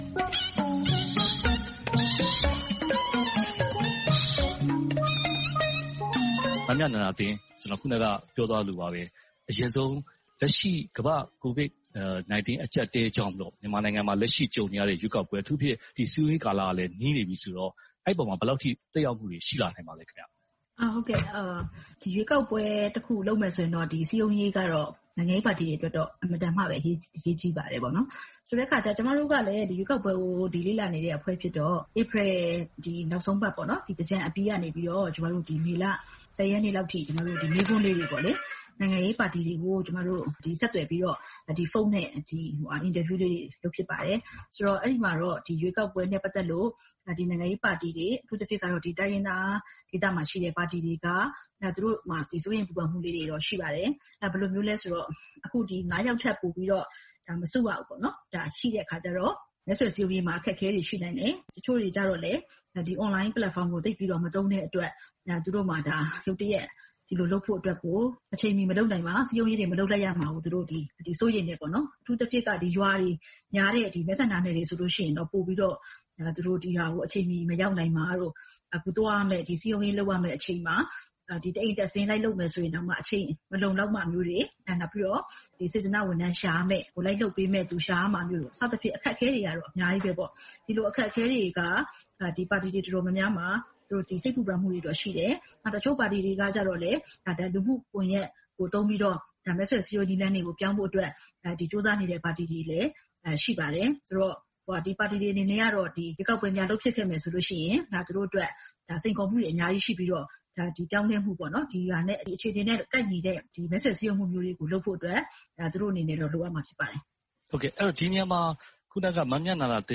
အွန်လိုင်းကနေ ဆက်သွယ်မေးမြန်းထားပါတယ်။